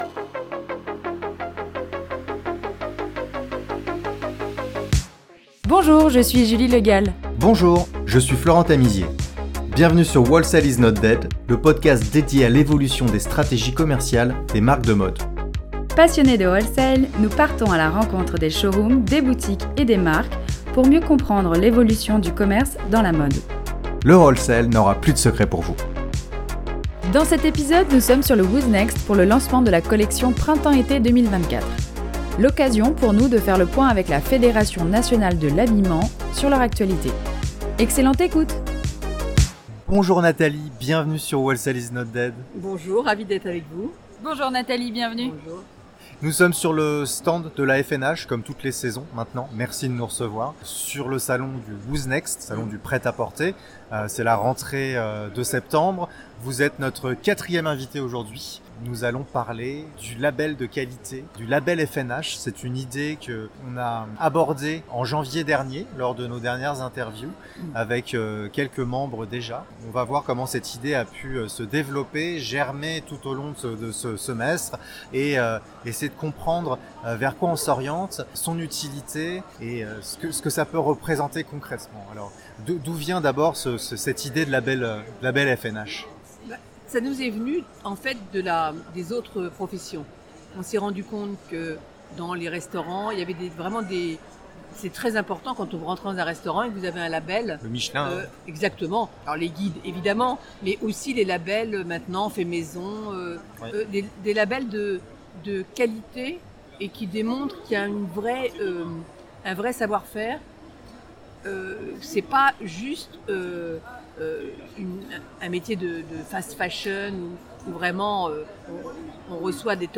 A l’occasion du dernier salon Who’s Next, la Fédération Nationale de l’Habillement a invité sur son stand WHOLESALE IS NOT DEAD pour échanger avec les commerçants et les administrateurs de la Fédération sur la situation actuelle du marché français, le rôle de la FNH auprès des détaillants et du gouvernement et ses ambitions pour l’année à venir.